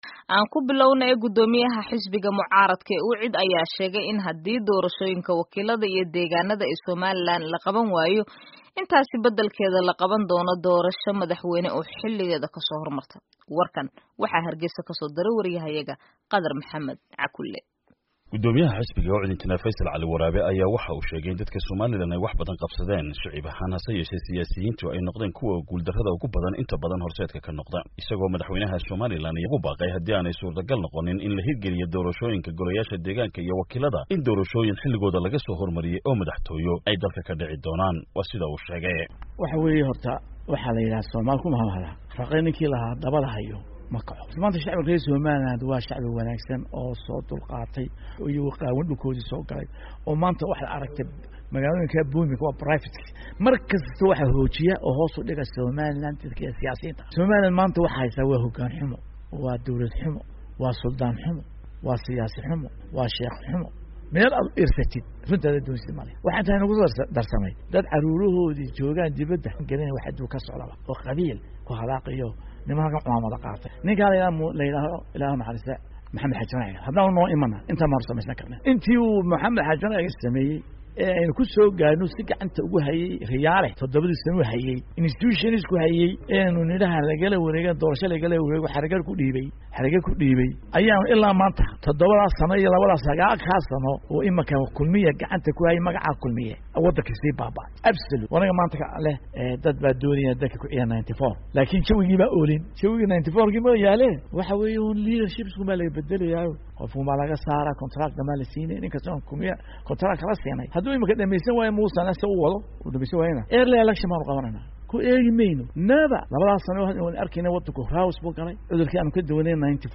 Dhagayso:Waraysi Xiiso Badan Oo laga Qaaday Gudoomiyaha Xisbiga Uicd iyo